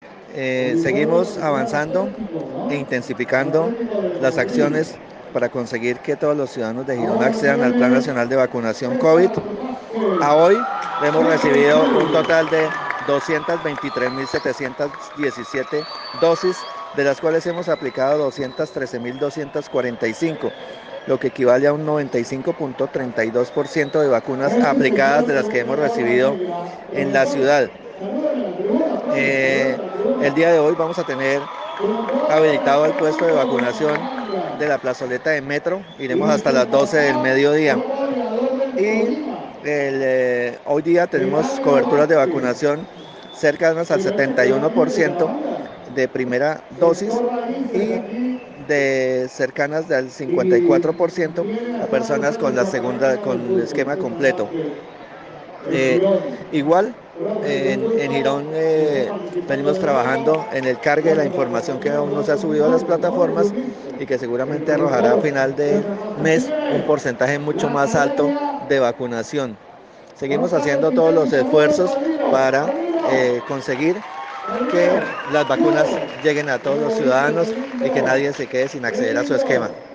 John Forero - Secretario Local de Salud.mp3